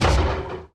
mob / irongolem / hit2.ogg
should be correct audio levels.